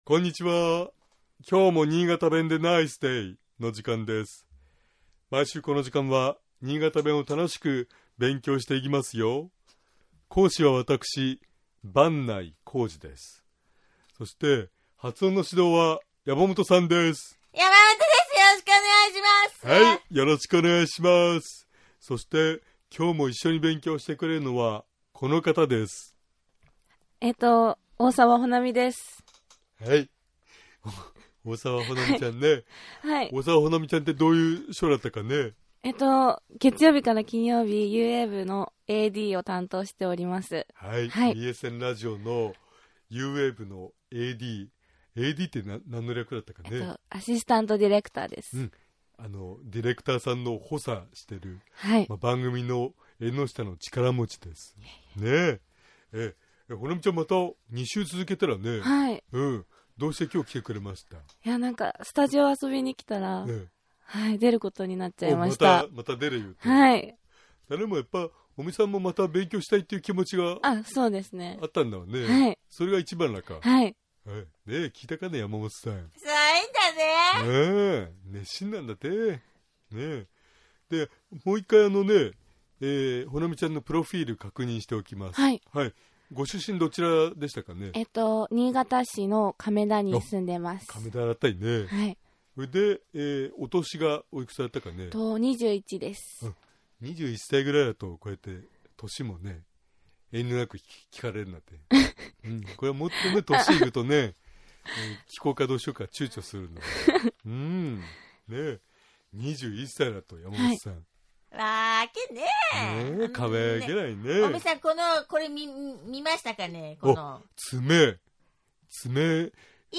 また、「にいーげ」「ああーめ」「うんーめ」という風に、 言葉の真ん中付近をかなり伸ばして発音するのが、 新潟弁の形容詞の大きな特徴です。